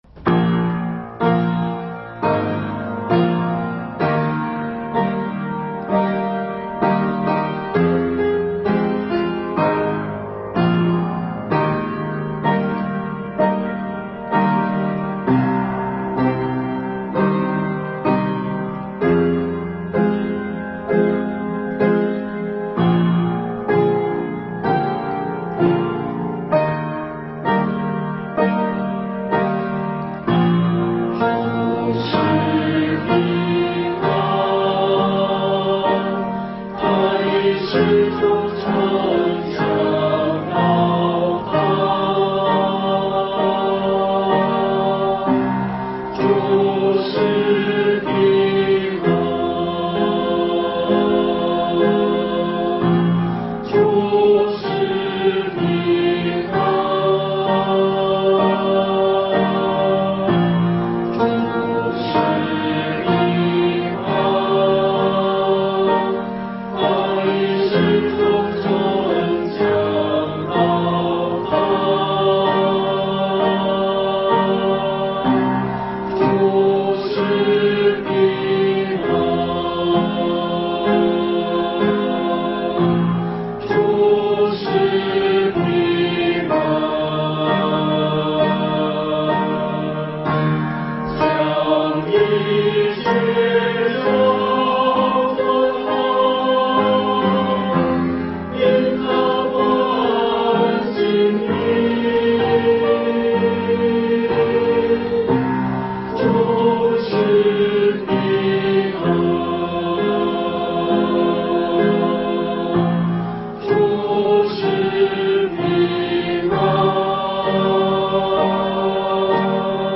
22-04-10受难周棕枝日